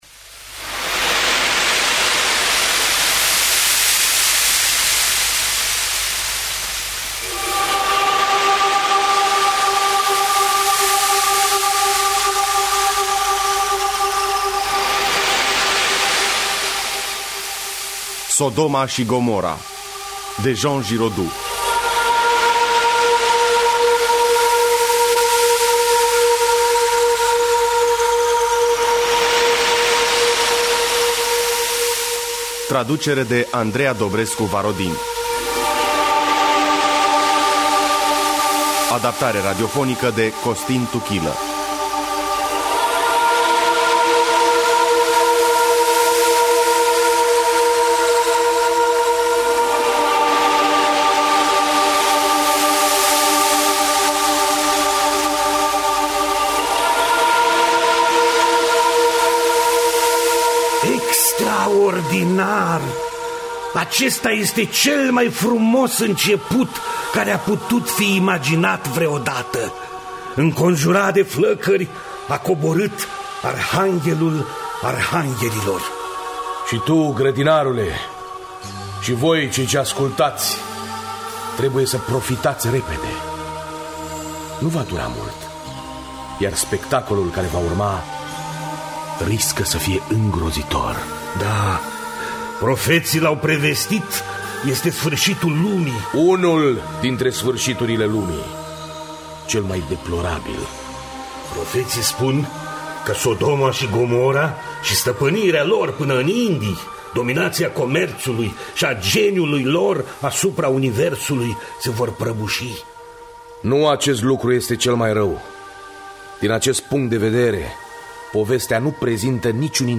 Sodoma şi Gomora de Jean Giraudoux – Teatru Radiofonic Online